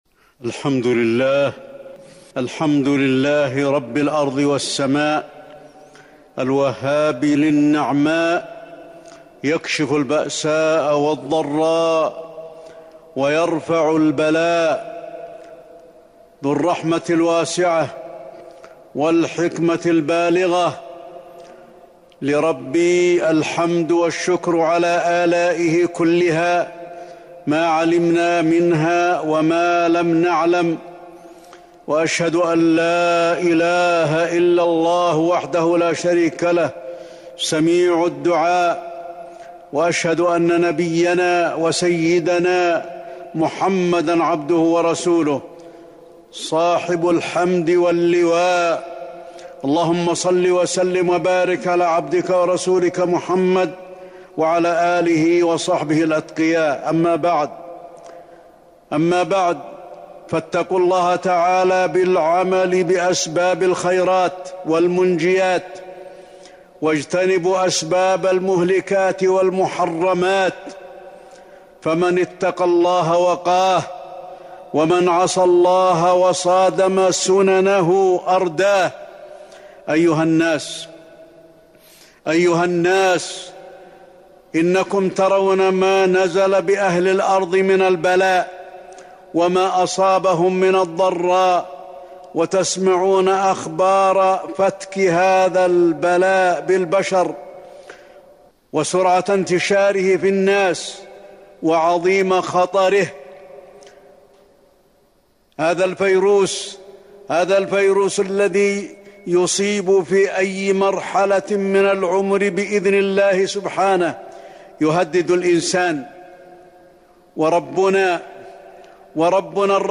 تاريخ النشر ٣ شعبان ١٤٤١ هـ المكان: المسجد النبوي الشيخ: فضيلة الشيخ د. علي بن عبدالرحمن الحذيفي فضيلة الشيخ د. علي بن عبدالرحمن الحذيفي فايروس كورونا